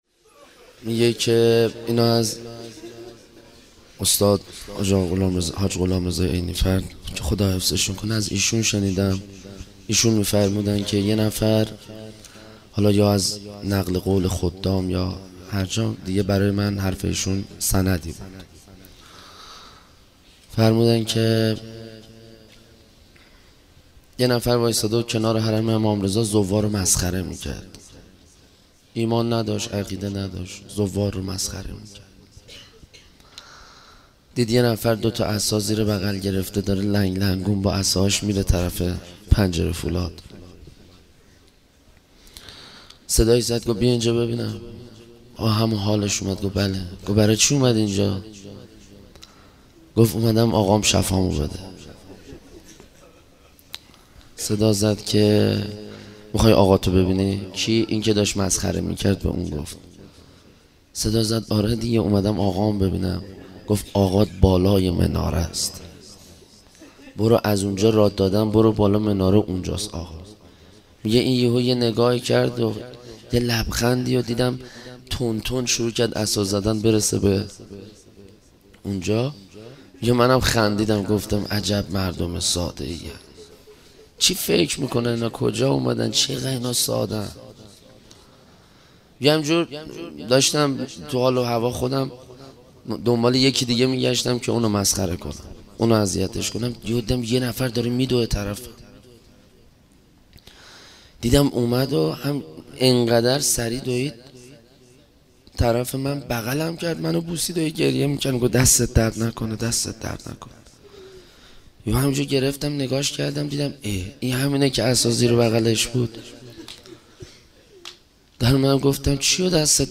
روضه امام رضا